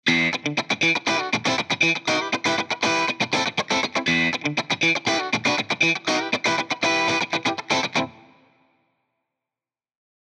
プリセットを選択しただけの状態ですが、このように即戦力として使えるサウンドやフレーズが豊富に収録されています。